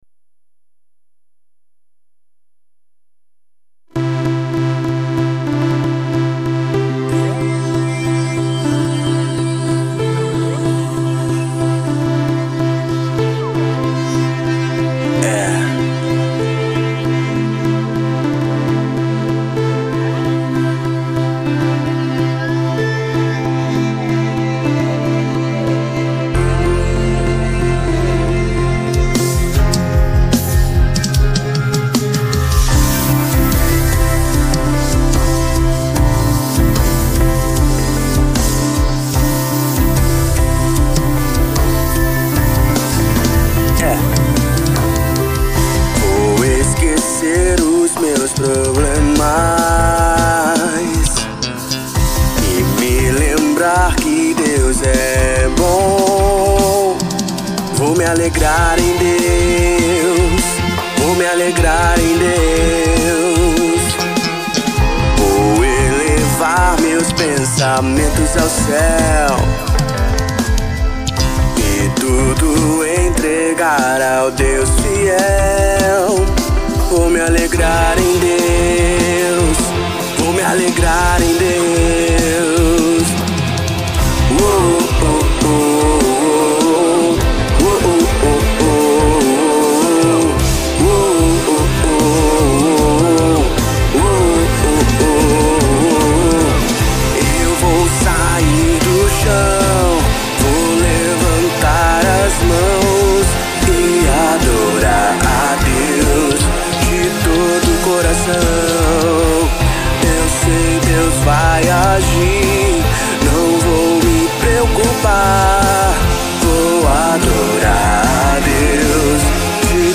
EstiloGospel